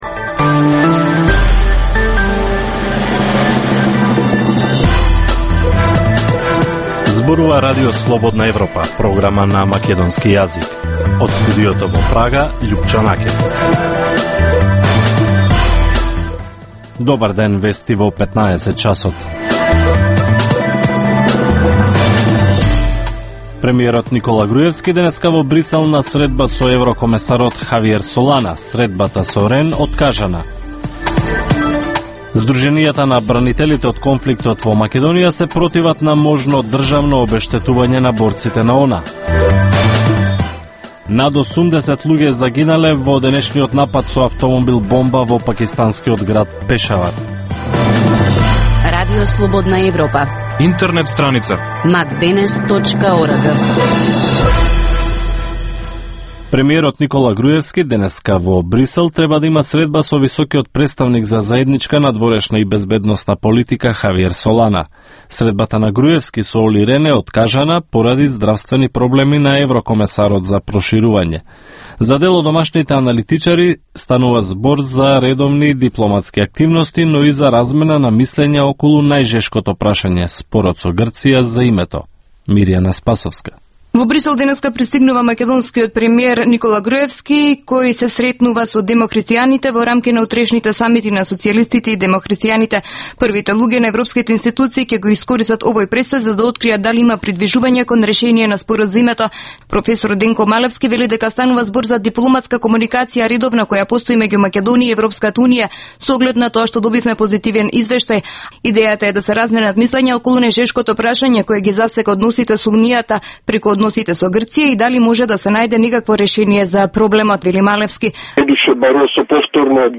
Информативна емисија, секој ден од студиото во Прага. Вести, актуелности и анализи за случувања во Македонија на Балканот и во светот.